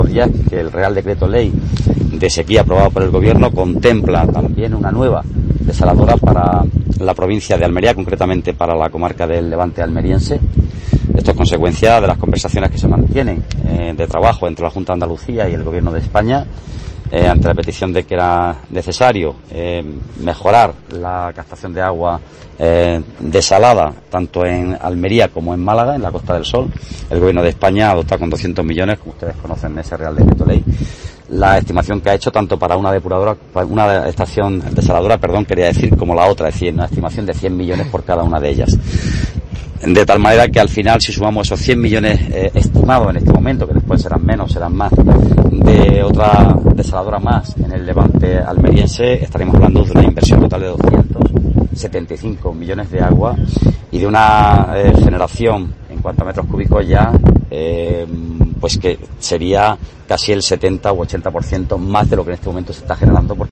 El delegado de Gobierno en Andalucía, Pedro Fernández